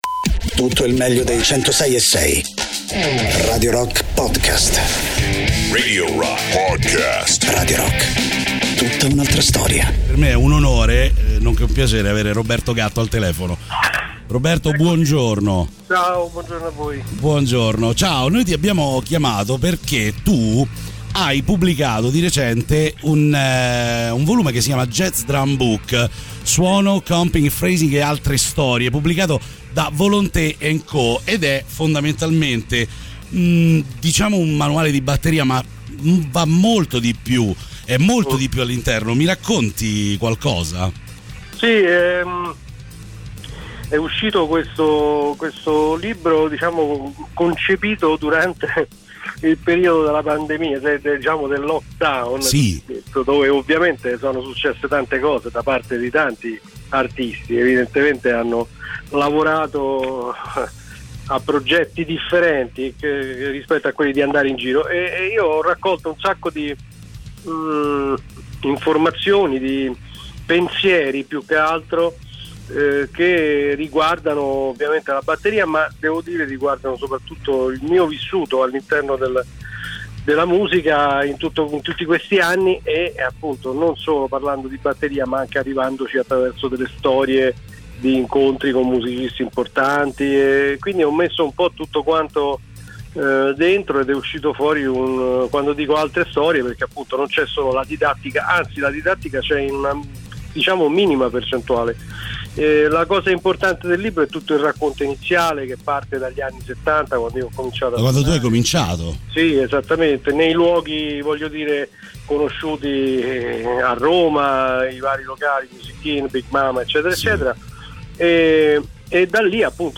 Interviste: Roberto Gatto (27-05-22)
ospite in studio